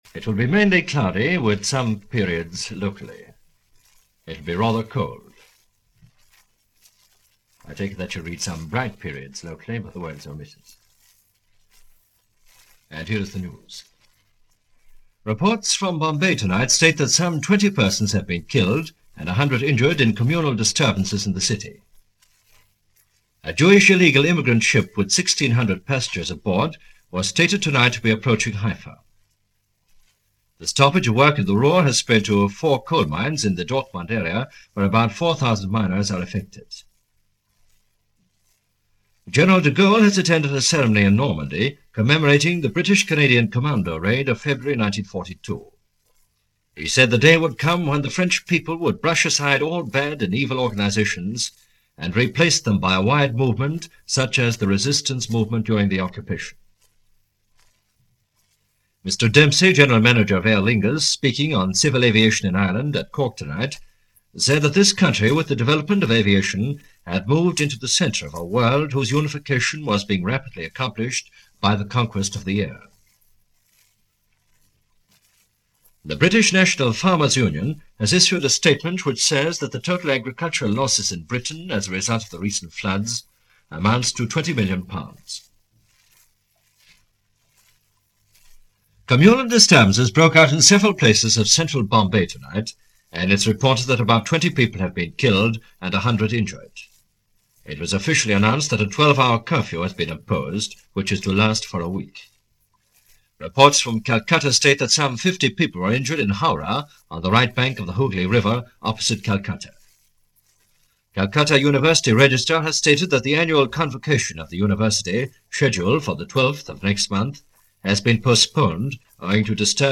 And that’s how the world was spinning, this last day of March in 1947 as presented by Radio Éireann in Dublin.